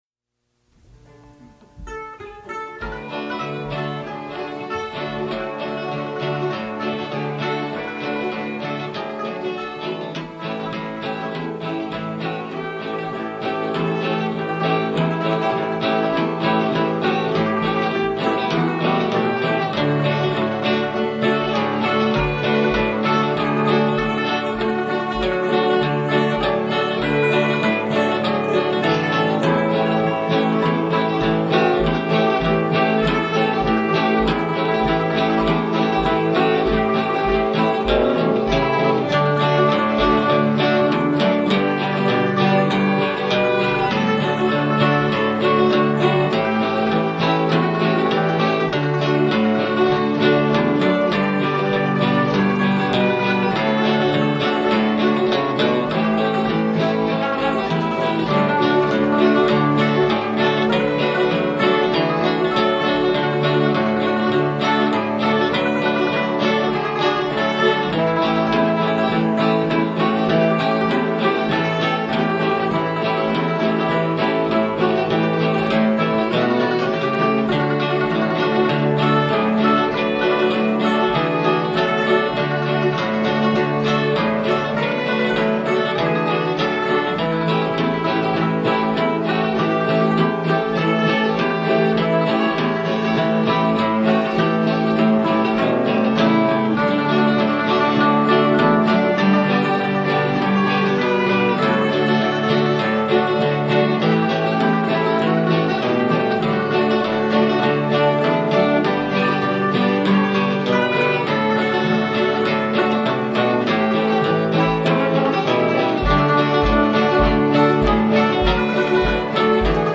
Fait partie de Recording music in the studio